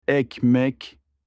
کلمه نان به ترکی استانبولی: Ekmek (اِکمِک)
bread-in-turkish.mp3